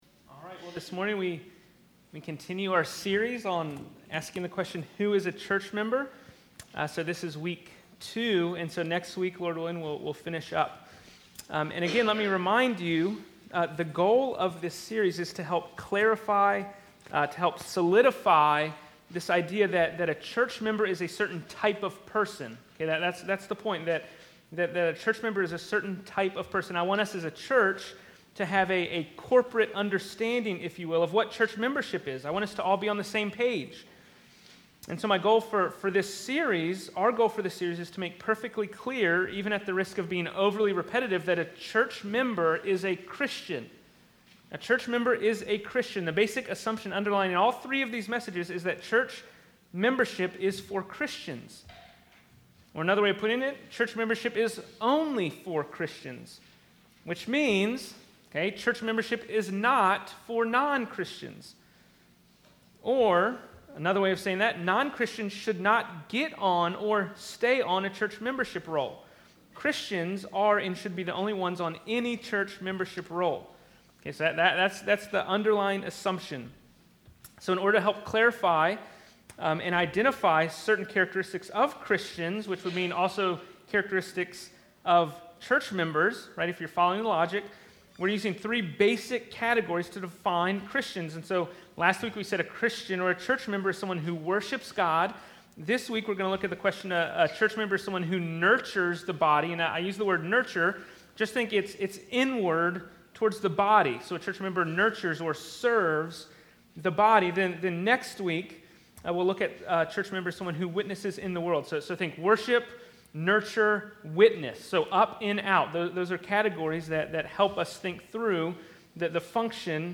Fox Hill Road Baptist Church Sermons